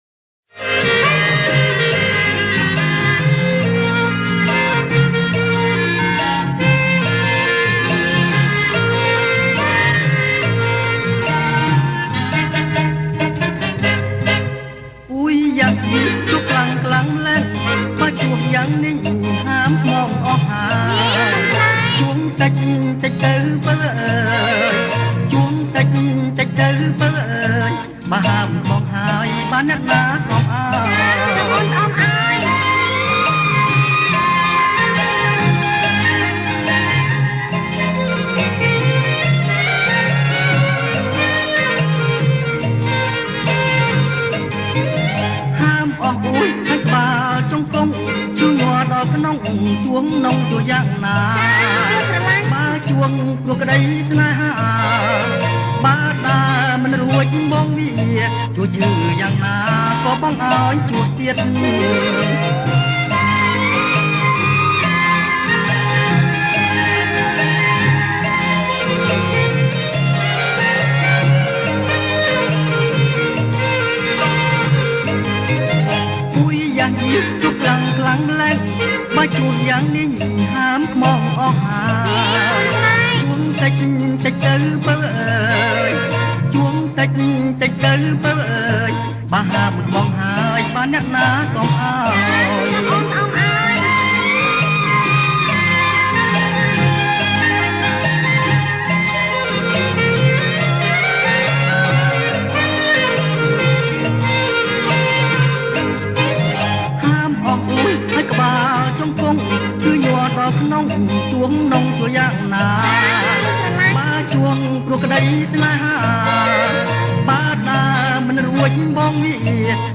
ប្រគំជាចង្វាក់